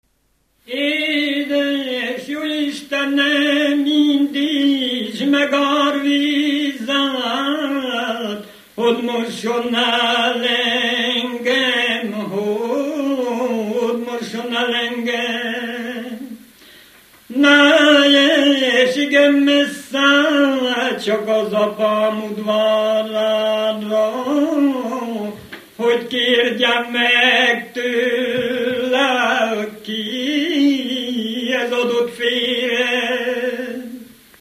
Erdély - Csík vm. - Gyimesfelsőlok
ének
Műfaj: Ballada
Stílus: 3. Pszalmodizáló stílusú dallamok